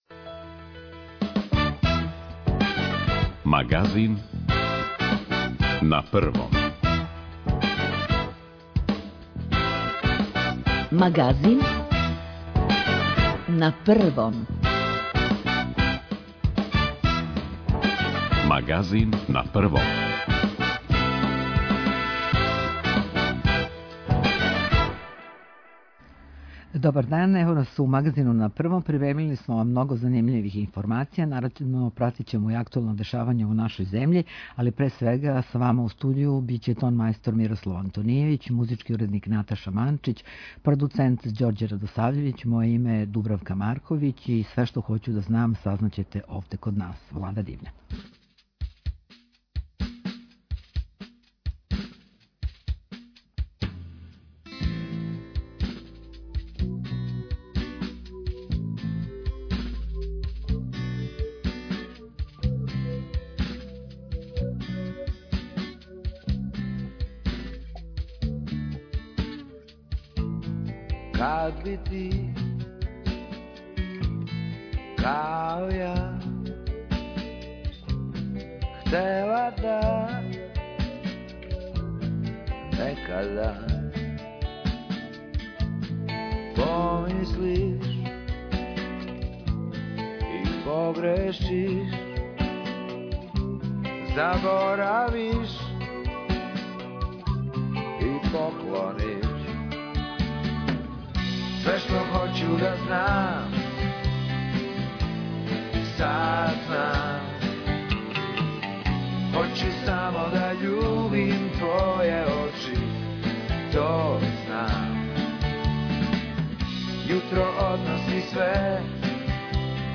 У складу са проглашеним ванредним стањем у Србији због пандемије корона вируса, Магазин на првом прилагодио је свој садржај новонасталој ситуацији. Доносимо најновије информације о епидемији која је захватила велики део света, тражимо савете стручњака о томе како се понашати у условима епидемије и ванредног стања, пратимо стање на терену, слушамо извештаје наших репортера из земље и света.